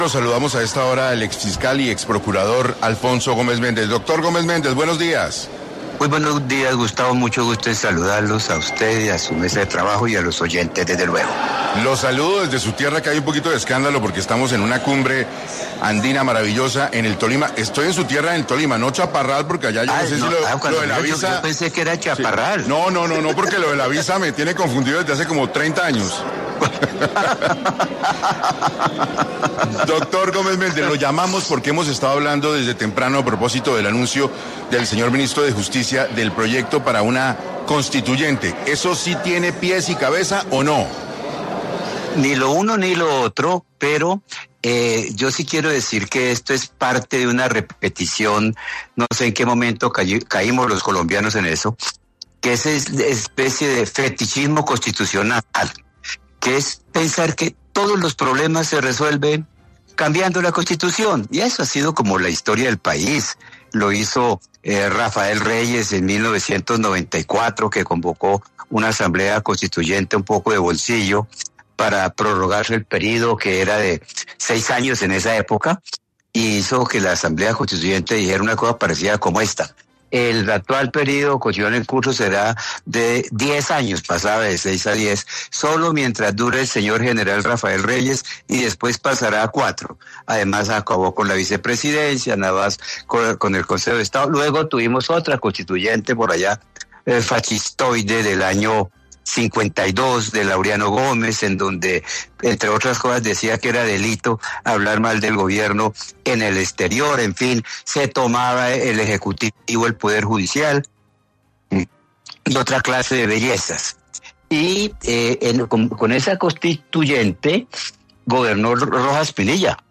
El exfiscal General de la Nación habló en 6AM del proyecto de ley de asamblea constituyente que busca reformar la constitución del 91 por parte del Gobierno Petro